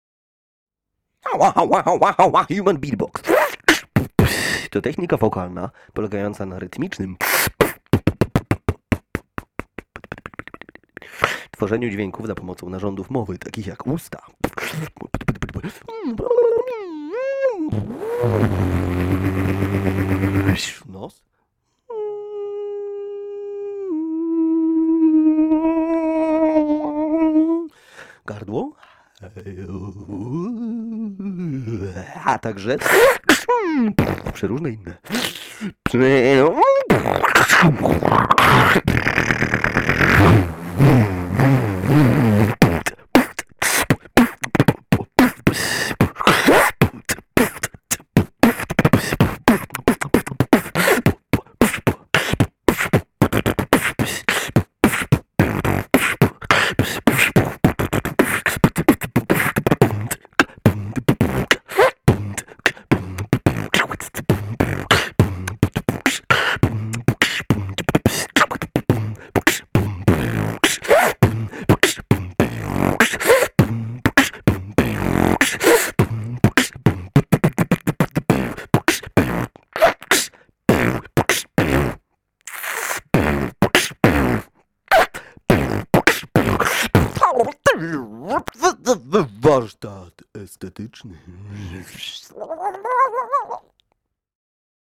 moja definicja human beatbox w audio